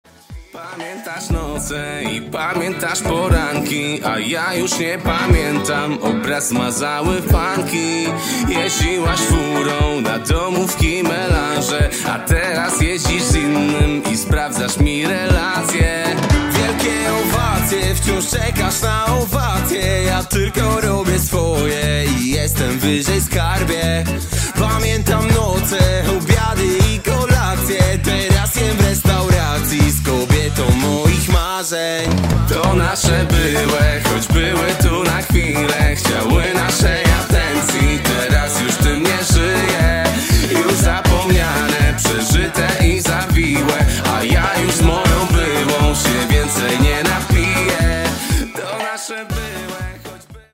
Pop muzyki